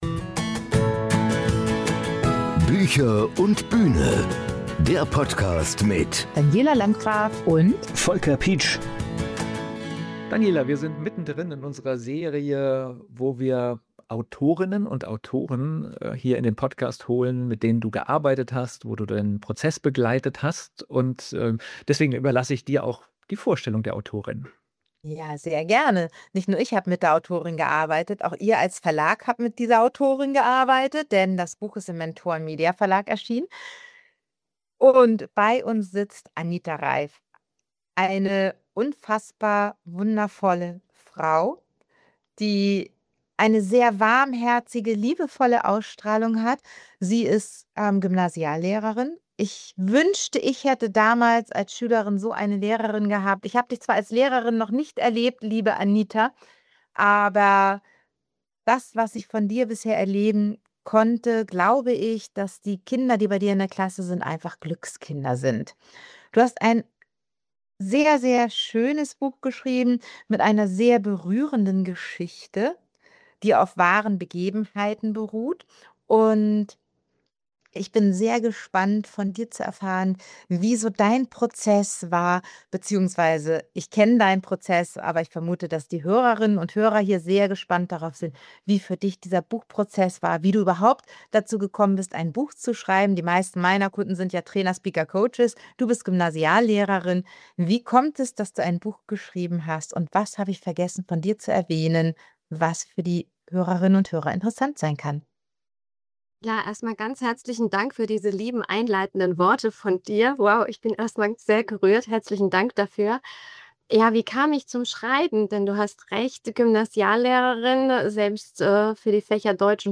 Im Gespräch geht es unter anderem um